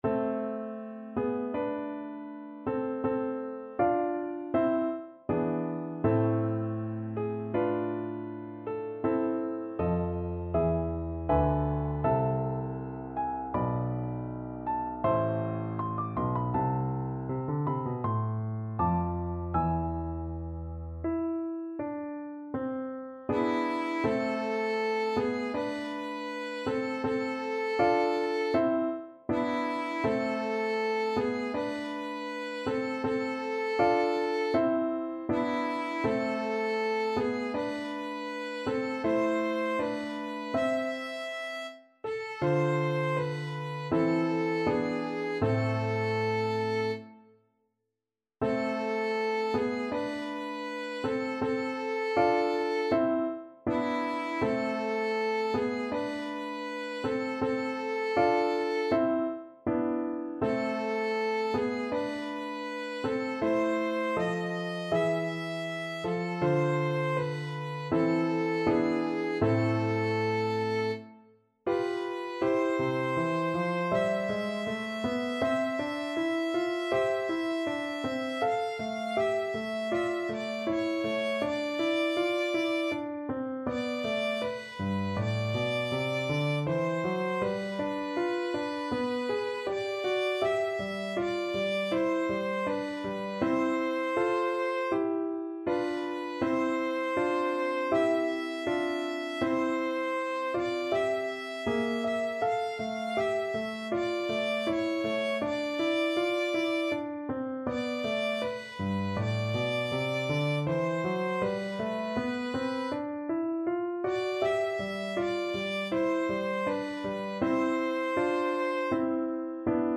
Key: A minor (Sounding Pitch)
Tempo Marking: Andante =c.80
Time Signature: 4/4
Instrument: Violin
Style: Classical